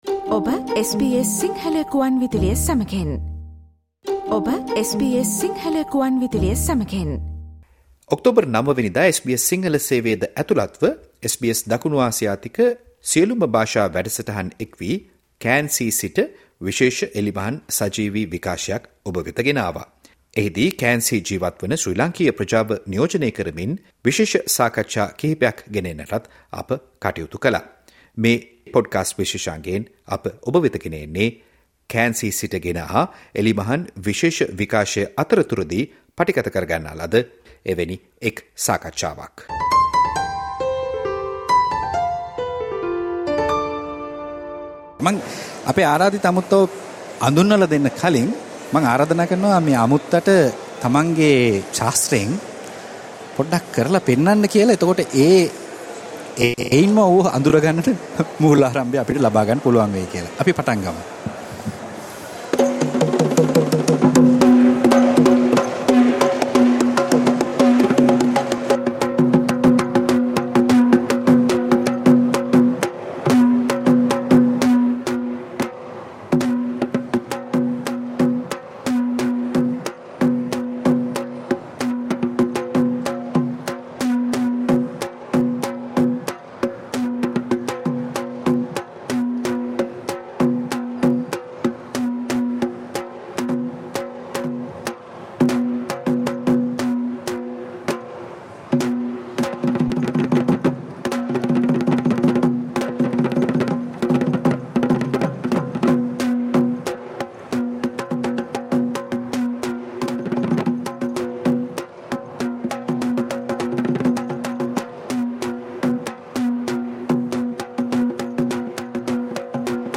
සජීව පටිගත කල පොඩ්කාස්ට් විශේෂාංගය
special Diwali outdoor broadcast at Cairns Central Shopping Centre